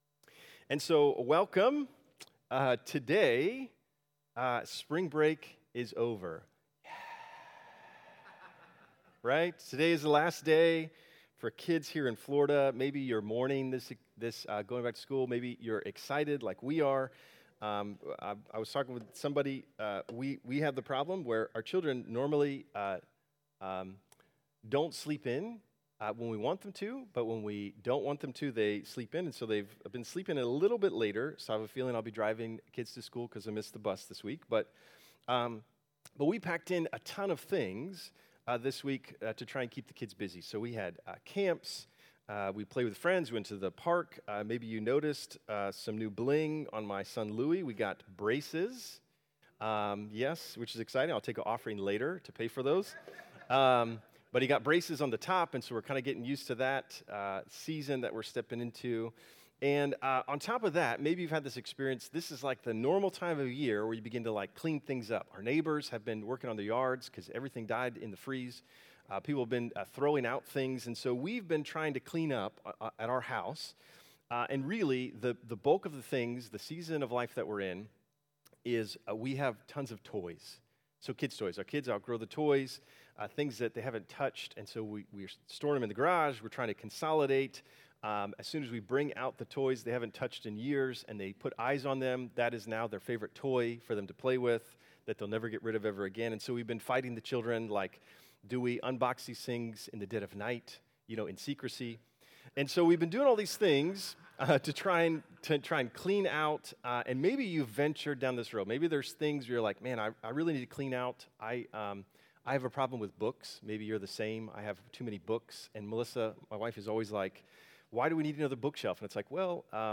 Download Download Reference John 11:1-45 From this series Current Sermon Lent 2026 Week 5